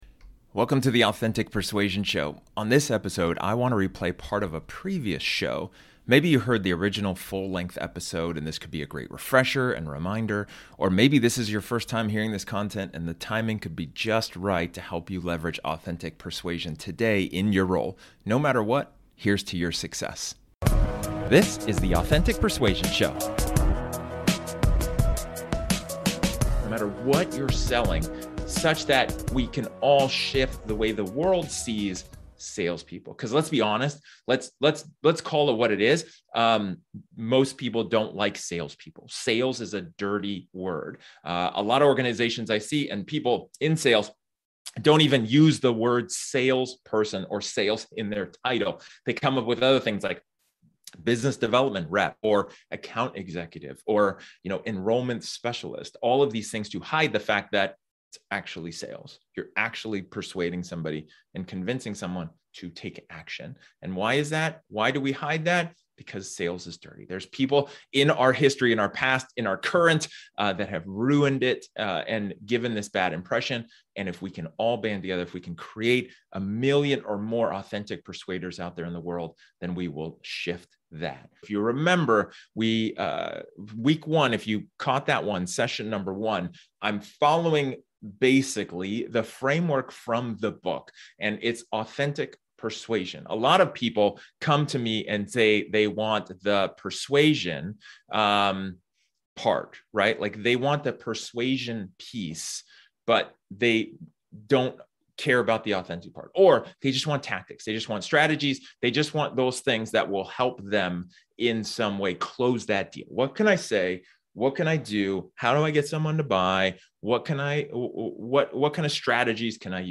This episode is an excerpt from one of my training sessions where I talk about the question: "Are you being a Leader?"